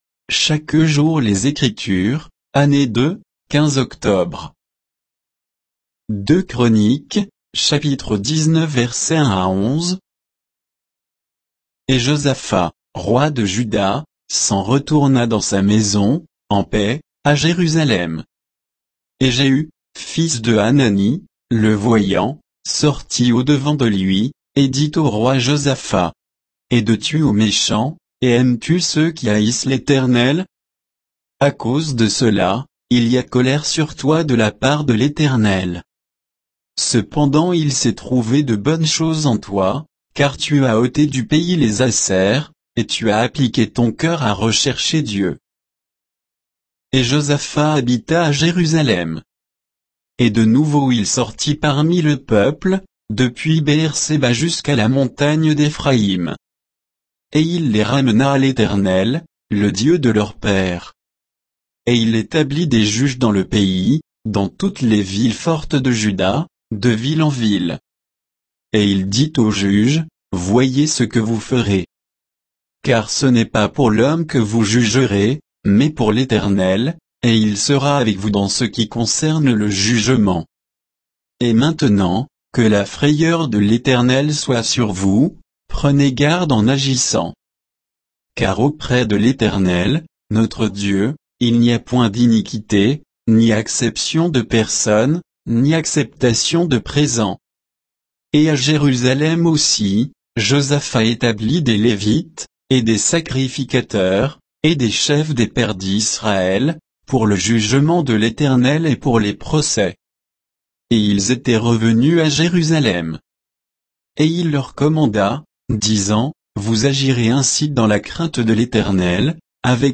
Méditation quoditienne de Chaque jour les Écritures sur 2 Chroniques 19